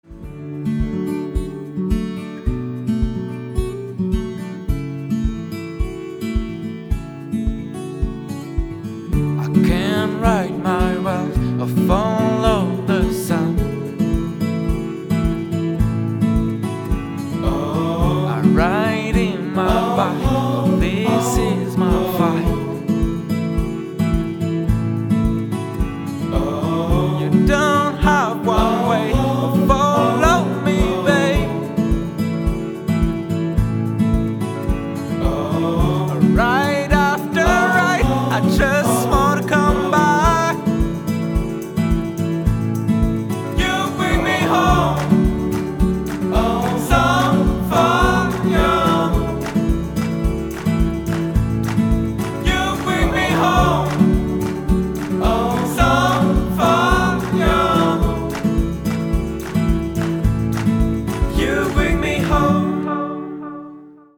гитара
мужской вокал
спокойные
indie pop
alternative
country pop